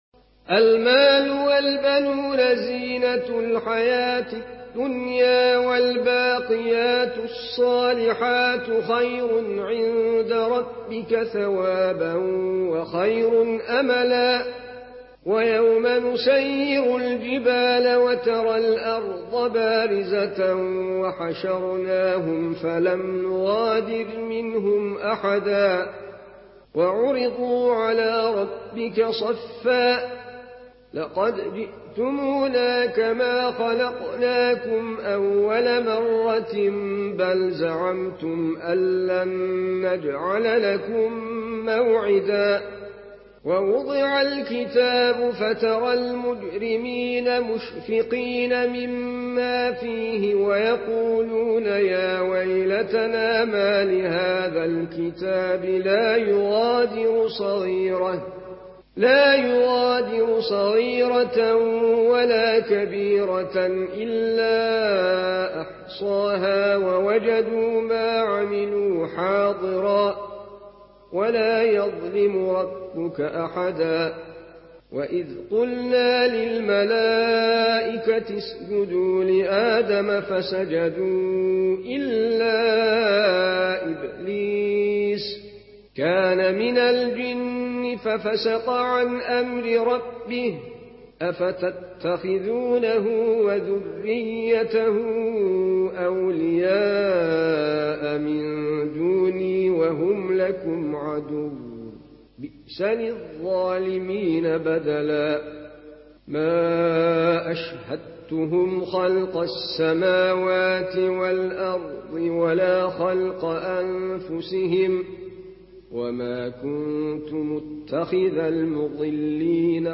Tayyar-AltikulacTayyar Altınkulaç’ın tilavetiyle sayfa sayfa olarak ayrılmış komple hatim seti.